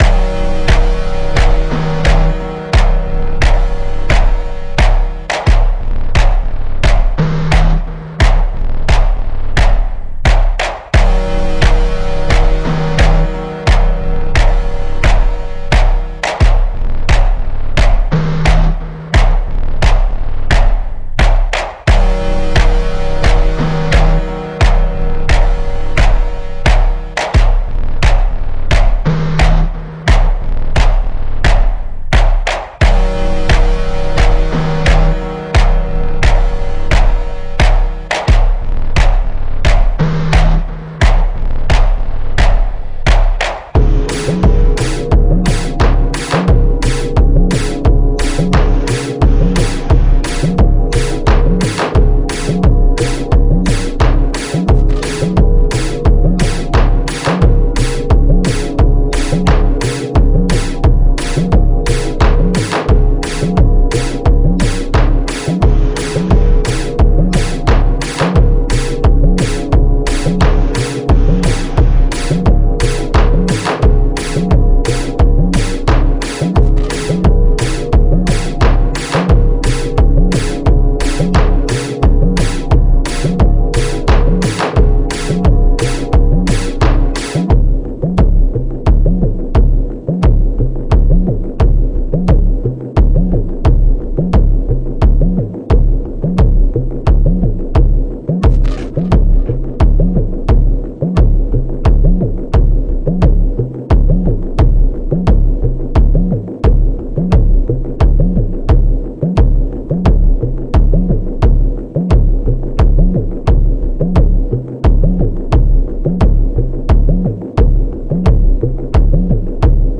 Танцевальная Музыка